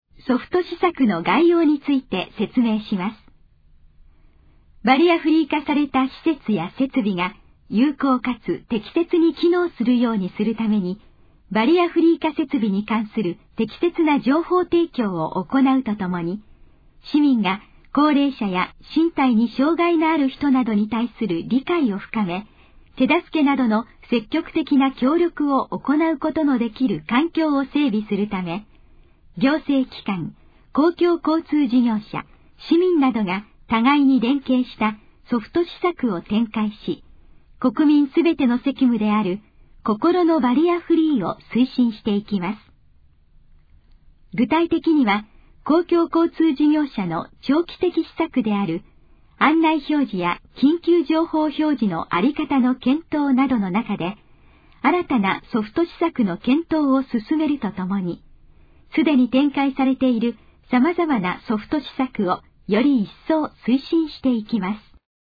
以下の項目の要約を音声で読み上げます。
ナレーション再生 約131KB